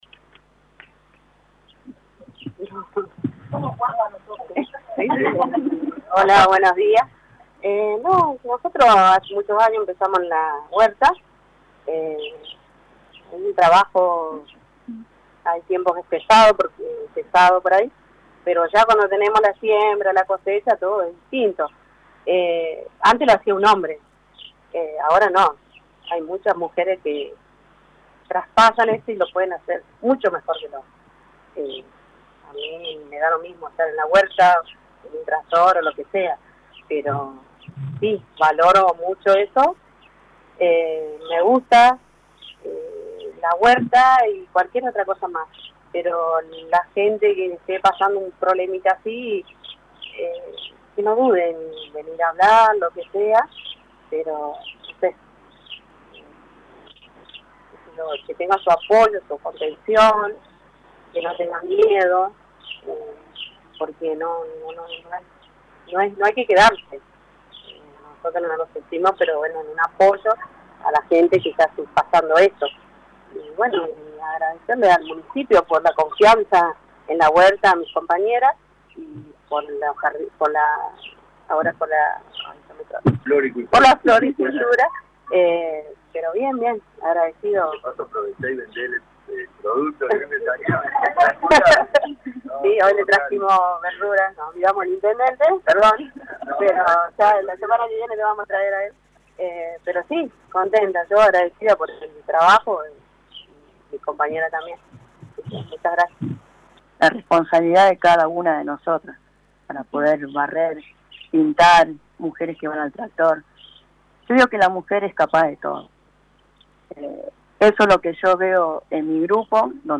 Testimonio de mujeres presentes:
TESTIMONIOS-25-11.mp3